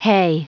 Prononciation du mot hay en anglais (fichier audio)
Prononciation du mot : hay